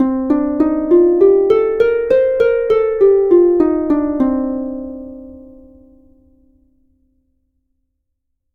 Escala dórica
harpa
sintetizador